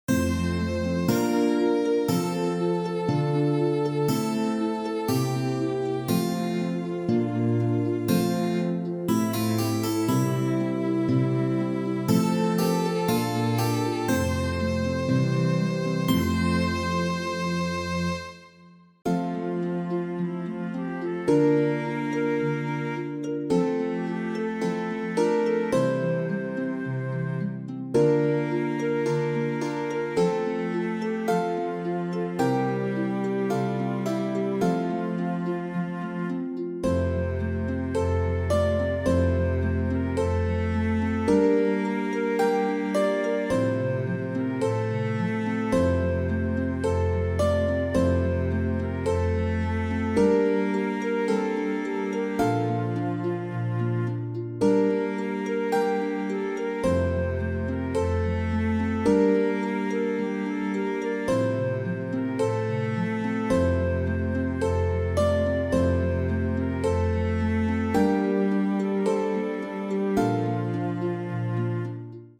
and a modern arrangement